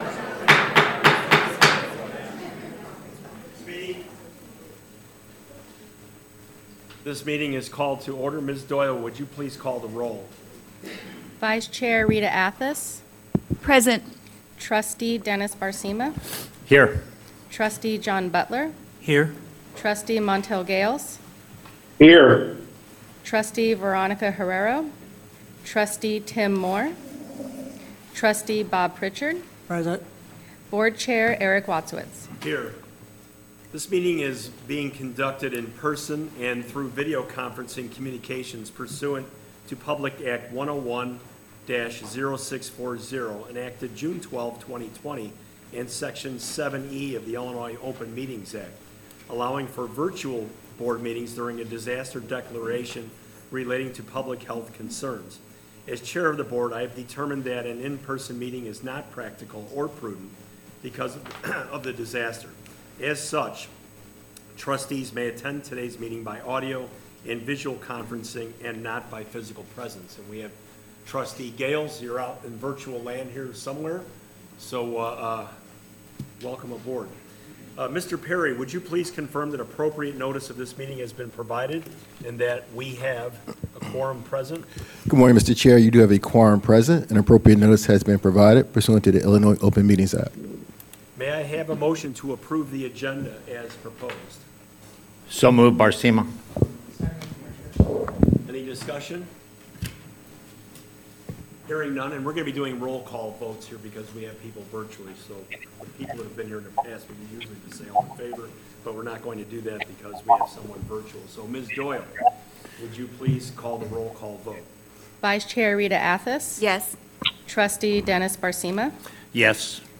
BOT Room, Altgeld 315